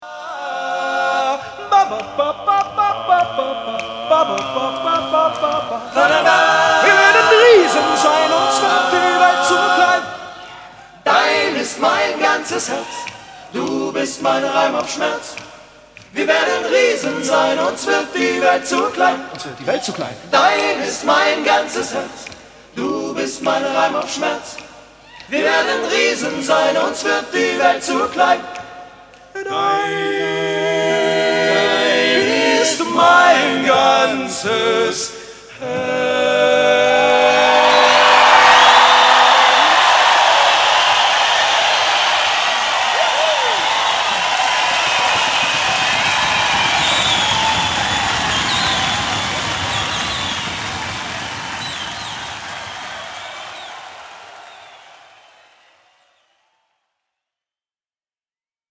a-capella, live